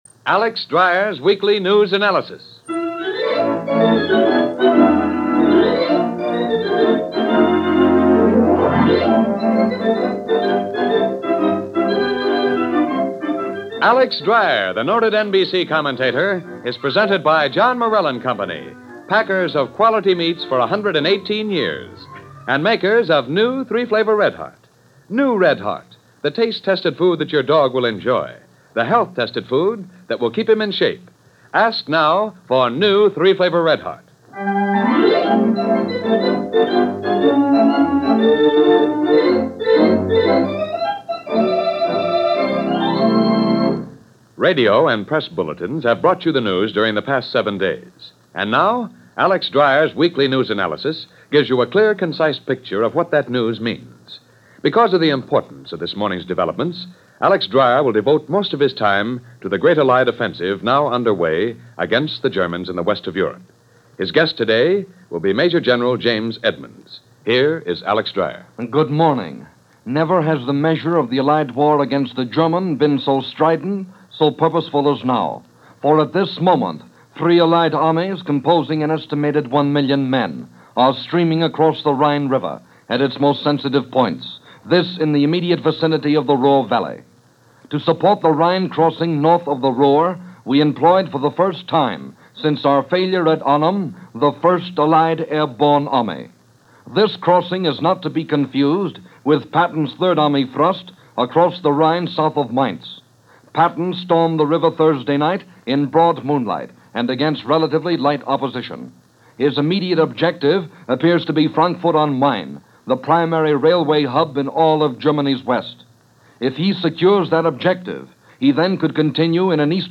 NBC – Alex Drier’s Weekly News Analysis – March 24, 1945 –